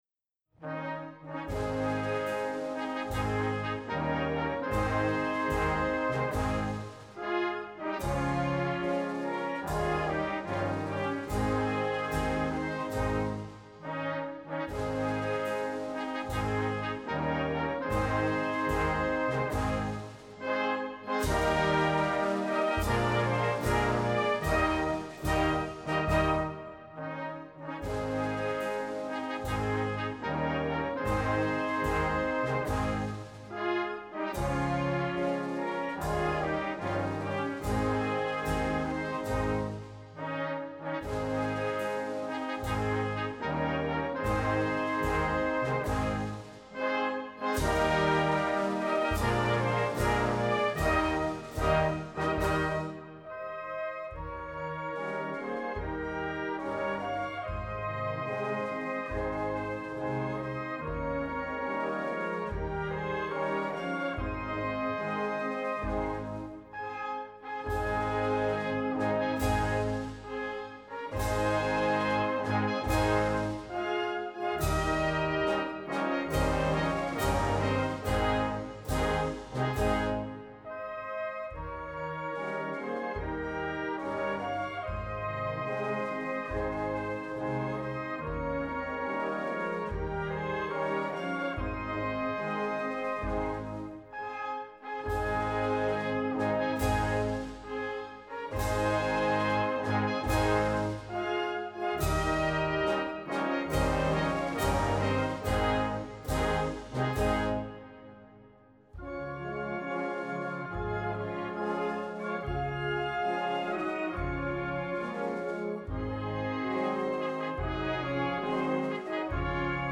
Gattung: Prozessionsmarsch
Besetzung: Blasorchester